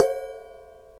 Drum Samples
C r a s h e s